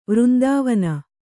♪ vřndāvana